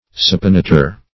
Supinator \Su`pi*na"tor\, n.
supinator.mp3